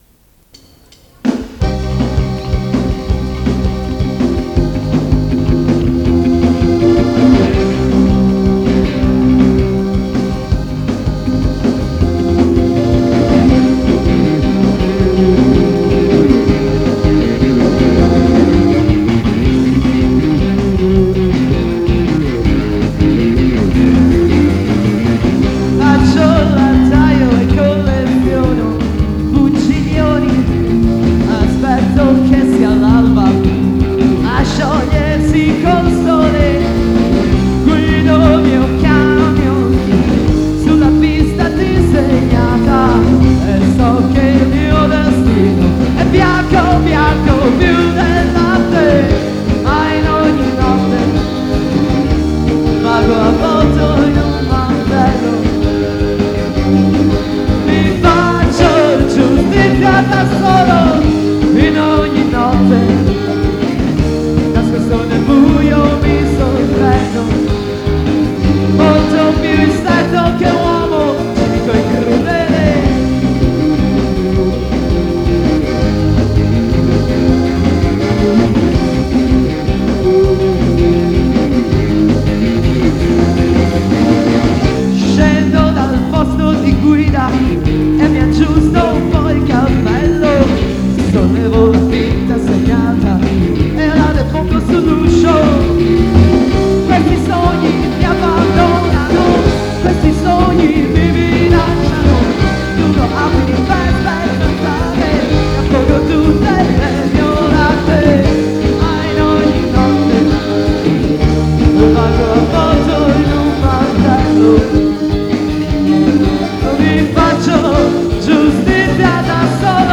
voce e chitarra
batteria
basso